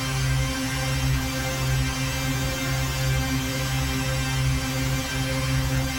DD_LoopDrone3-C.wav